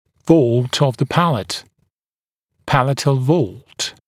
[vɔːlt əv ðə ‘pælət] [‘pælətl vɔːlt][во:лт ов зэ ‘пэлэт] [‘пэлэтл во:лт]свод нёба